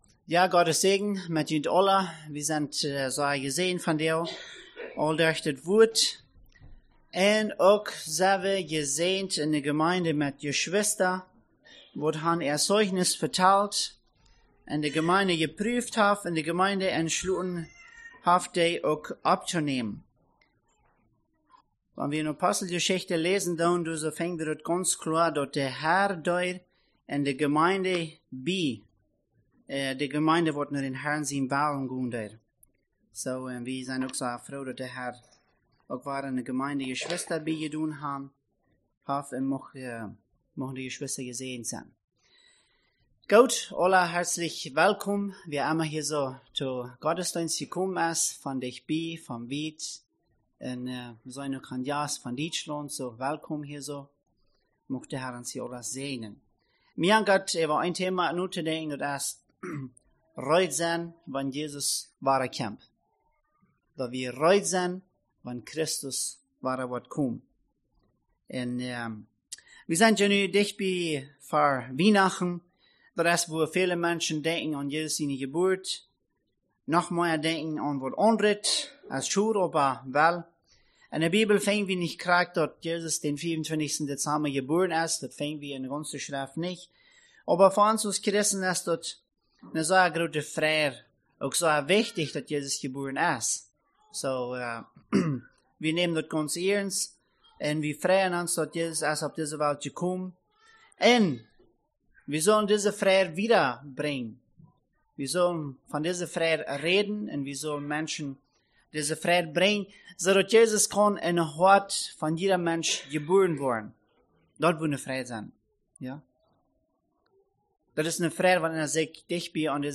Sunday Message